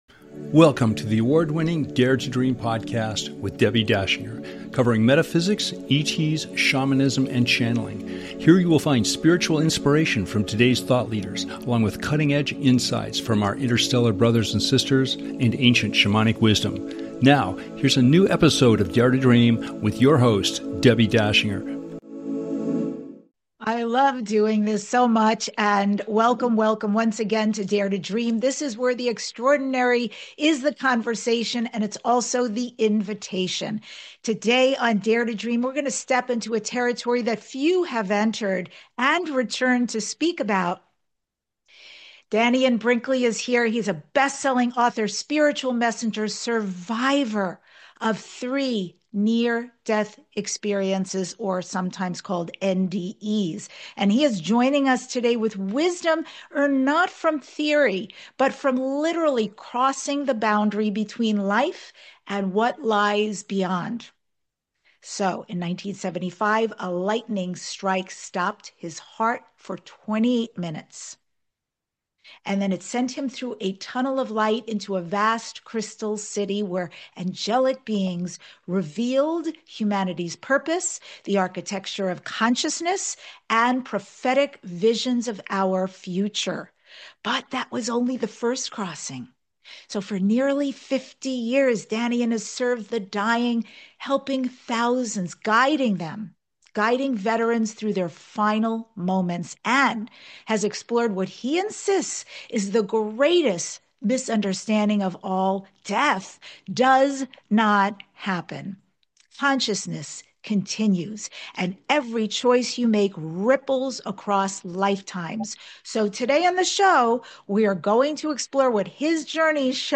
Talk Show Episode, Audio Podcast, Dare To Dream and DANNION BRINKLEY: 28 Minutes Dead.